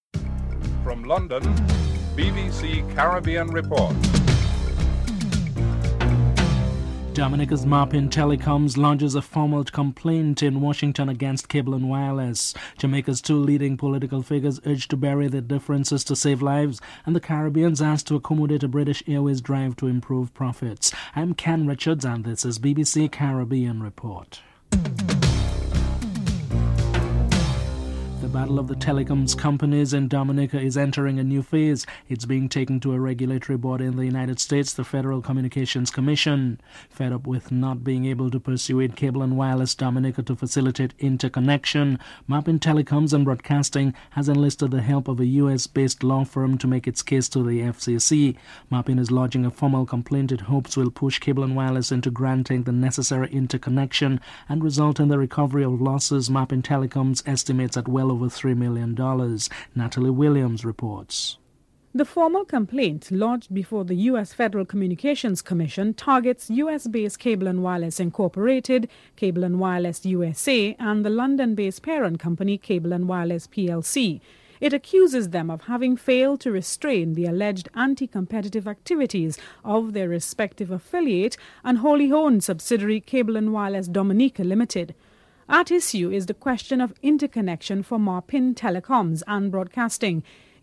1. Headlines (00:00-00:27)
Opposition Leader Bruce Golding is interviewed (08:09-11:48)